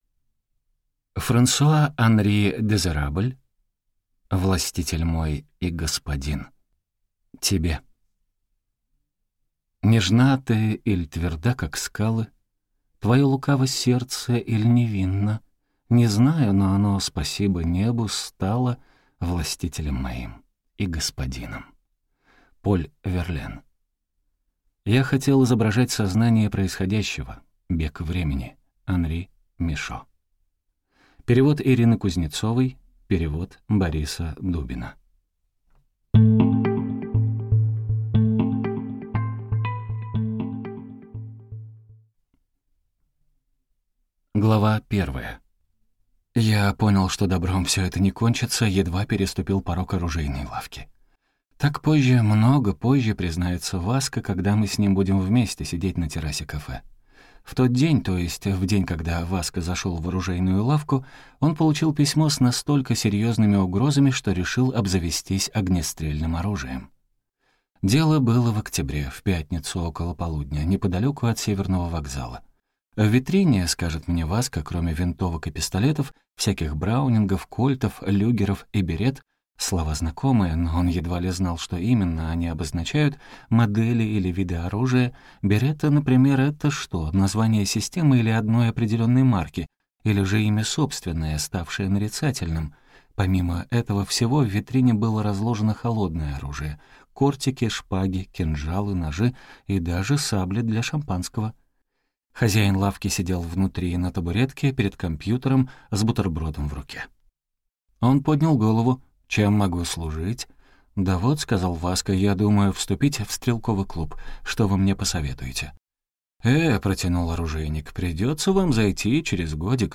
Аудиокнига Властитель мой и господин | Библиотека аудиокниг